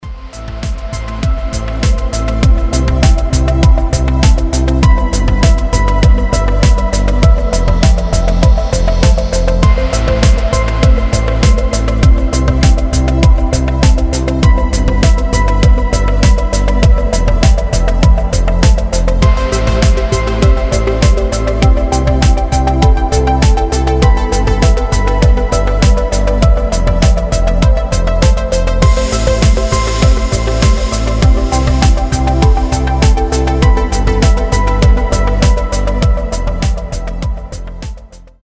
• Качество: 320, Stereo
Electronic
без слов
красивая мелодия
progressive house
melodic trance